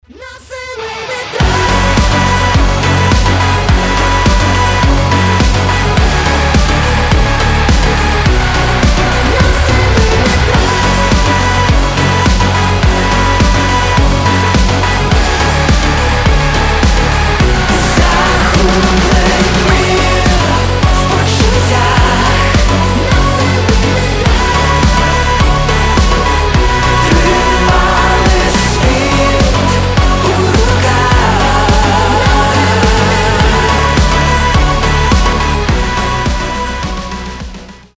• Качество: 320, Stereo
громкие
мощные
Драйвовые
Alternative Rock
тяжелый рок